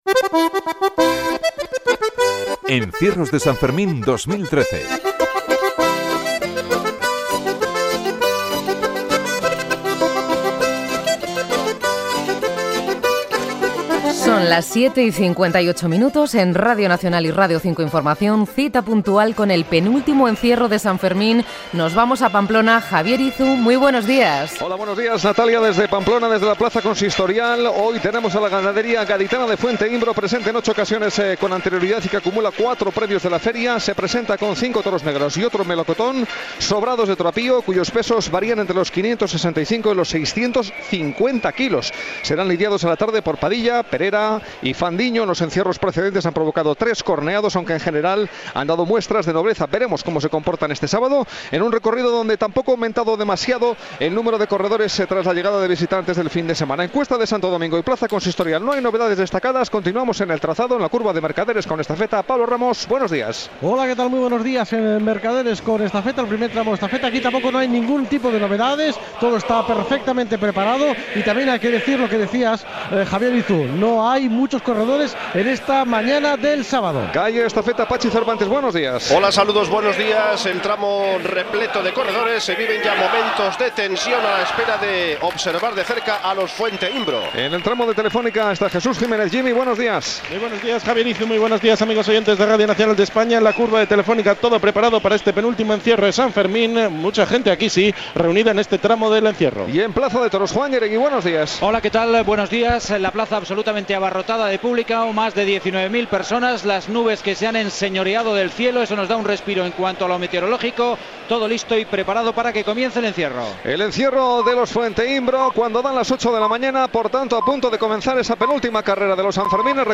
Careta del programa, hora i transmissió, des de Pamplona, del "encierro" de la ramaderia de Fuenteimbro. Connexió amb els diversos punts del recorregut i narració dels esdeveniments. A l'entrada de la plaça de braus es produeix un amontegament de persones. Comentaris finals i careta de sortida.
Informatiu